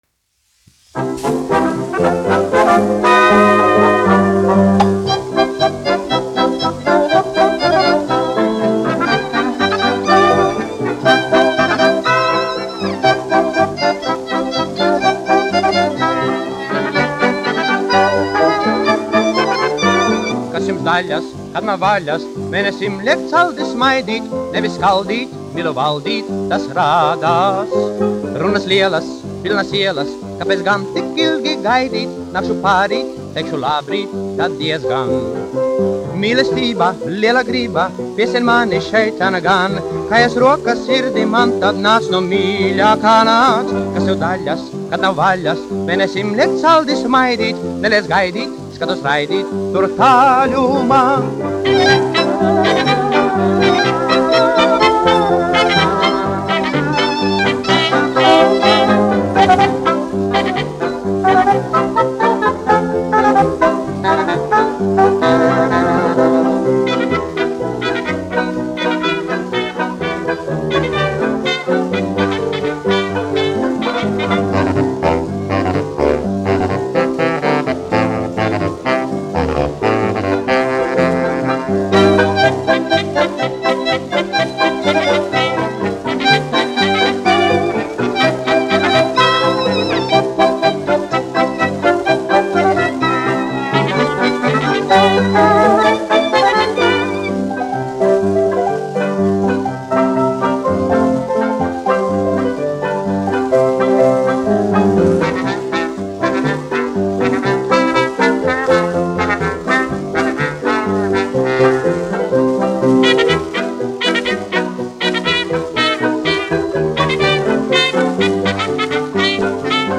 1 skpl. : analogs, 78 apgr/min, mono ; 25 cm
Populārā mūzika
Latvijas vēsturiskie šellaka skaņuplašu ieraksti (Kolekcija)